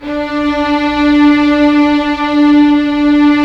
Index of /90_sSampleCDs/Roland LCDP13 String Sections/STR_Violins I/STR_Vls1 Sym wh%